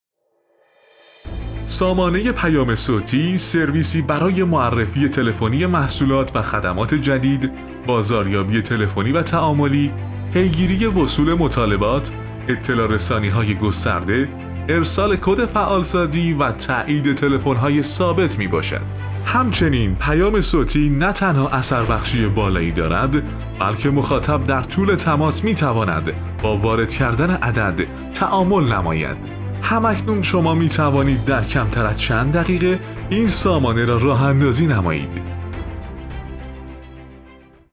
پیام-صوتی-نمونه.wav